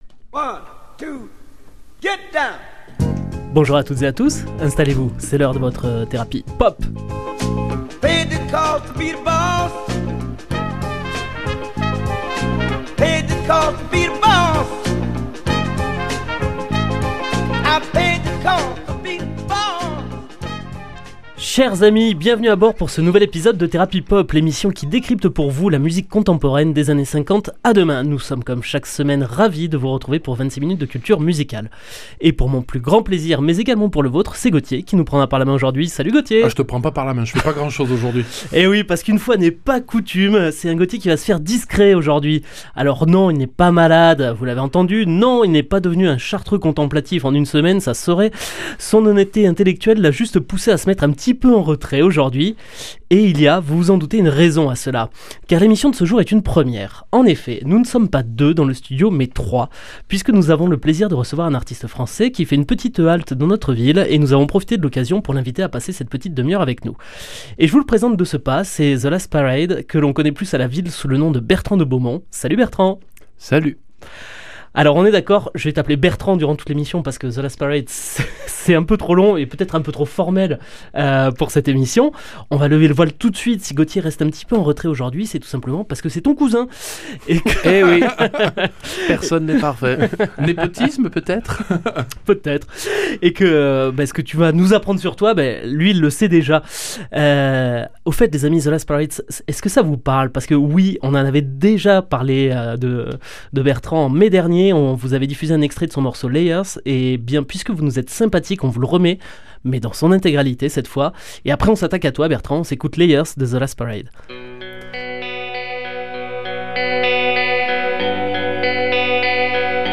Interview de The Last Parade